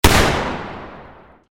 gunshot_bang.mp3